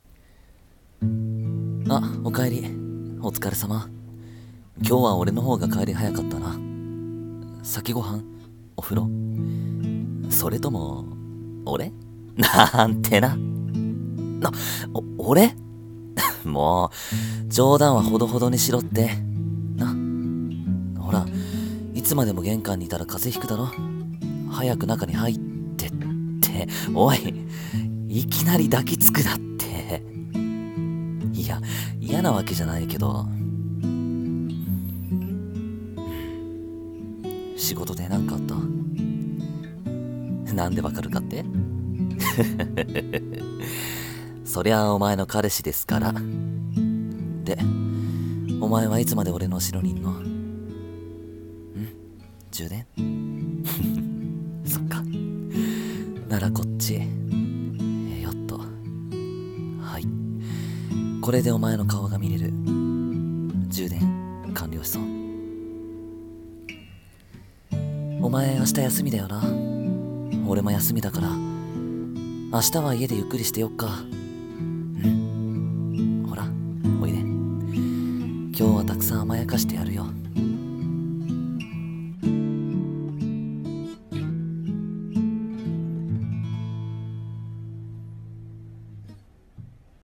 【声劇】おかえりの充電 BGM：BGM 癒し